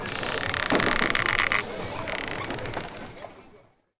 warehouse.wav